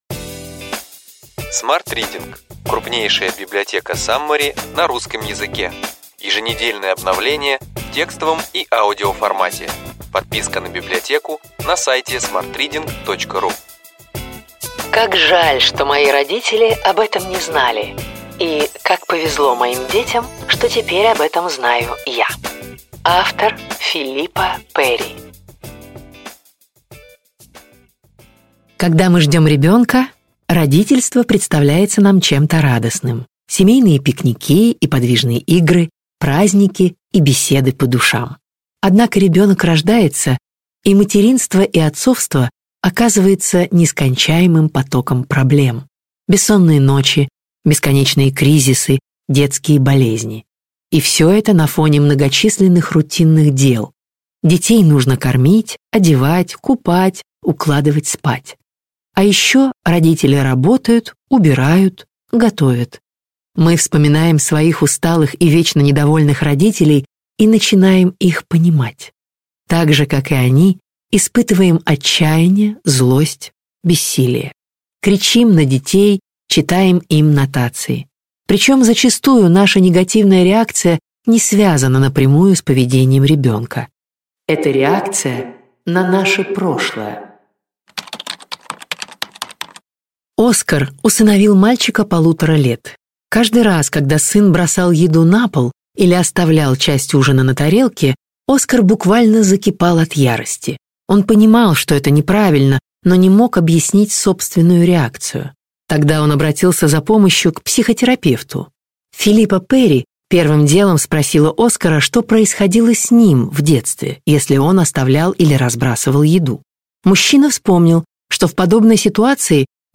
Аудиокнига Ключевые идеи книги: Как жаль, что мои родители об этом не знали (и как повезло моим детям, что теперь об этом знаю я).